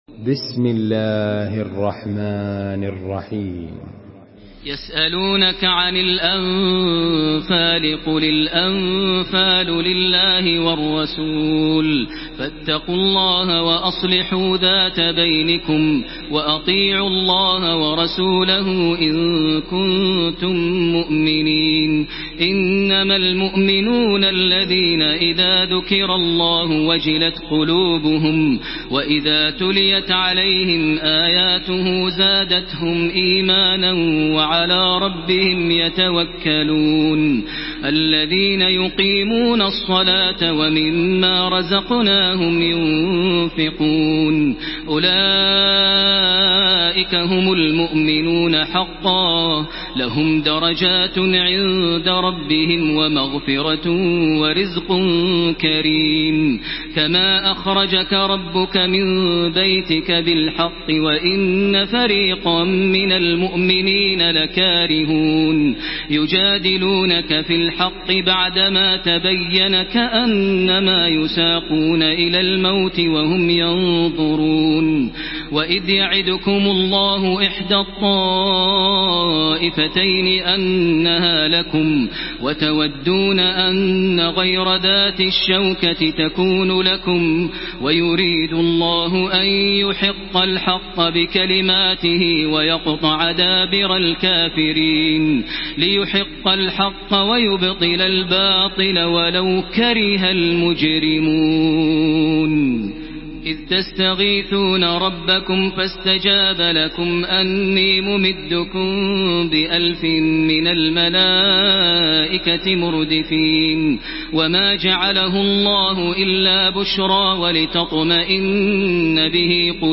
تراويح الحرم المكي 1433
مرتل حفص عن عاصم